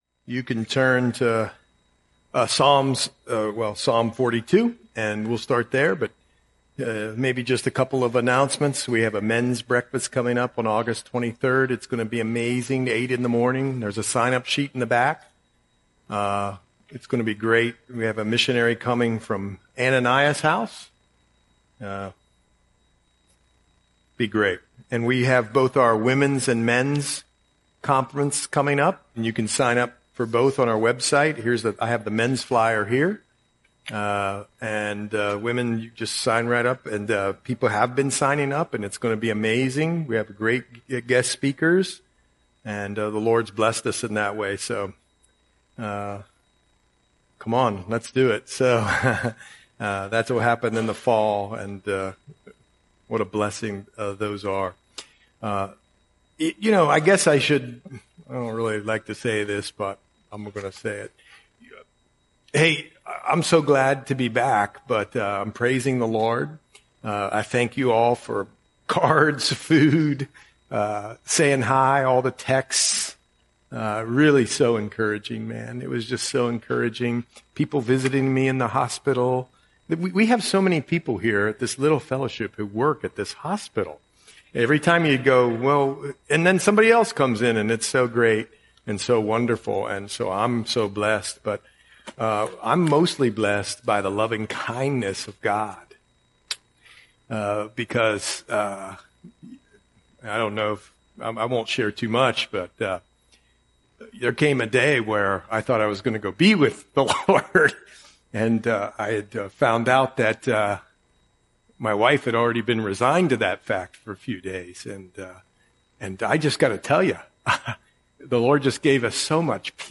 Audio Sermon - July 23, 2025